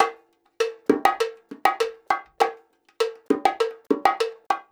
100 BONGO3.wav